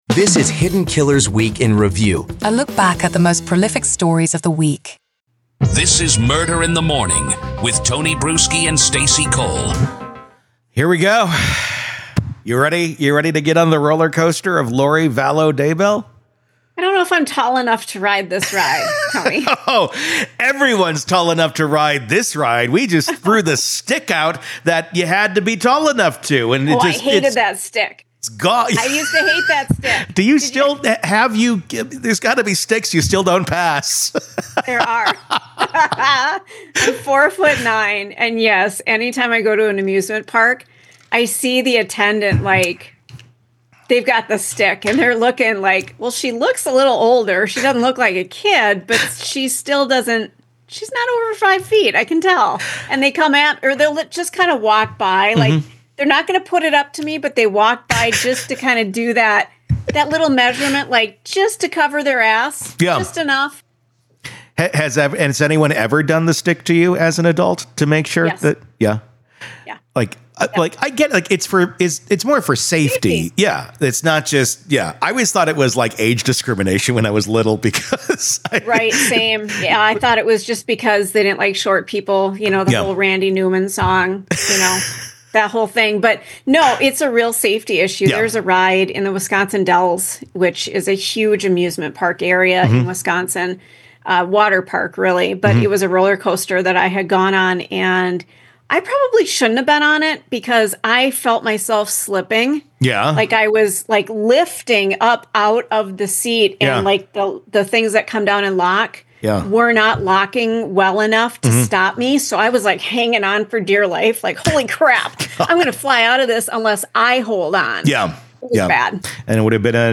Full Courtroom Coverage
Prosecution Opening Statements In Full-WEEK IN REVIEW